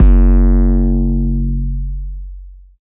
808 Kick 18_DN.wav